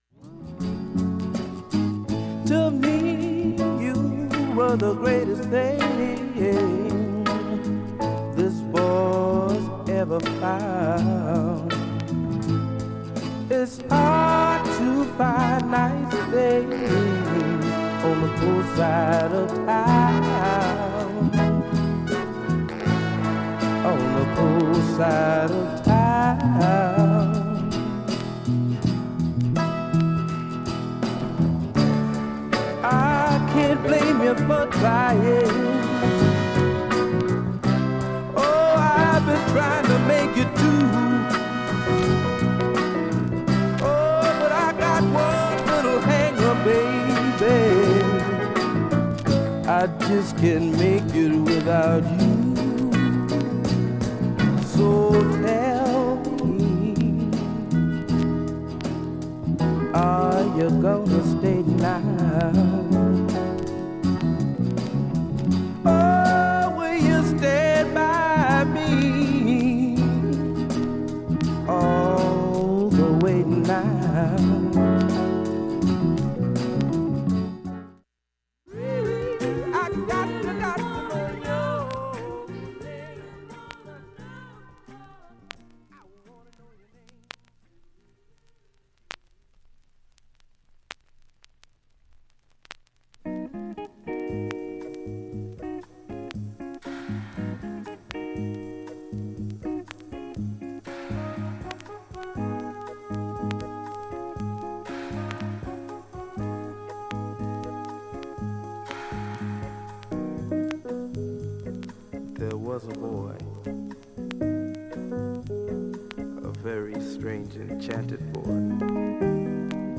音質良好全曲試聴済み。
2,(1m18s〜)A-2終りから22回プツ出ます。
145秒の間に周回プツ出ますがかすかです。
4,(4m31s〜)B-4始めかすか33回と５回プツ。
単発のかすかなプツが8箇所
ソフト＆メロウな異色のソウル・アルバム